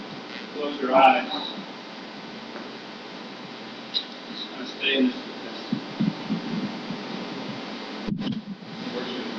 Well, this Sunday the wall wart for the wireless mic receiver got bumped so it was off, unfortunately this wasn’t found till after our pastor started speaking and we got him using a wired mic because we couldn’t figure out the problem.(We usually can’t see the wireless receiver because the recording laptop blocks it) Surprisingly his voice got picked up by one of the mics on stage even though there wasn’t one near him so I have what he was saying but it is faint and I have to turn the gain way up and then I get alot of noise.